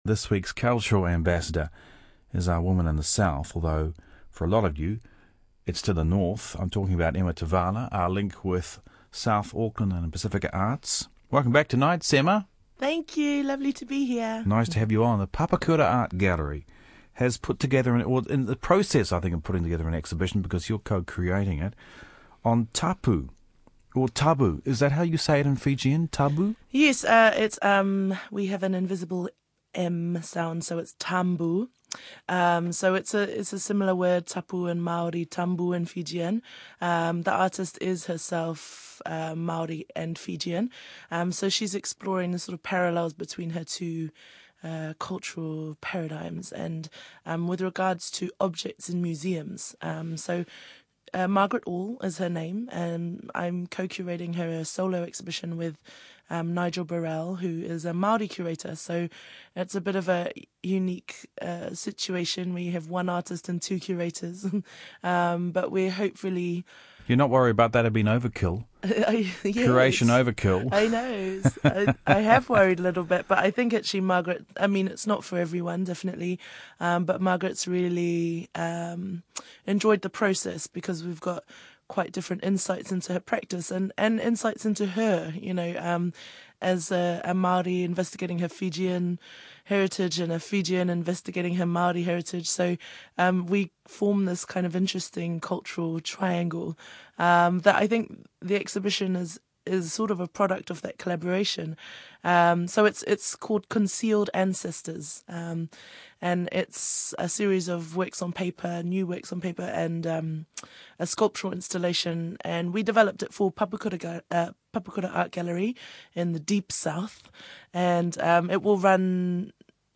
discuss the exhibition on Radio New Zealand